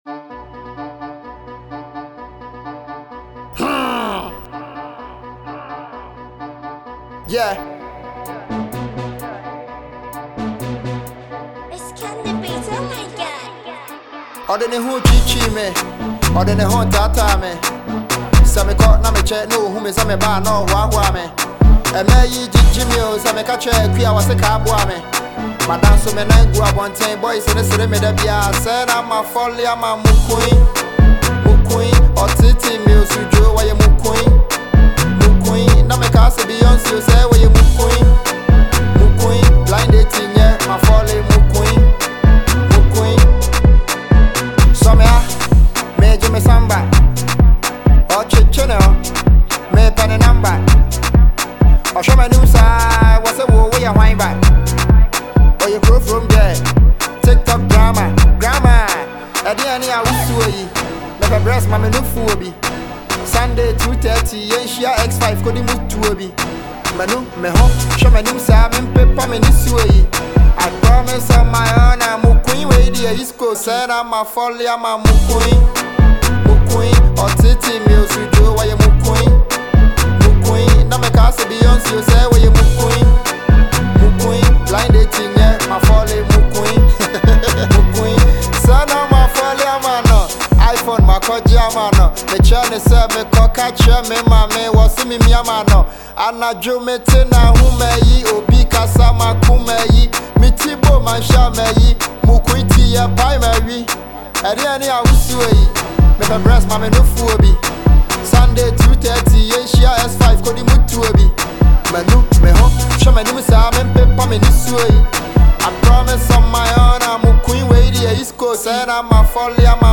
Ghanaian rapper